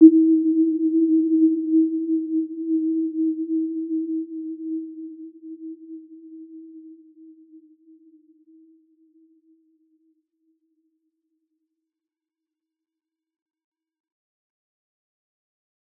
Warm-Bounce-E4-mf.wav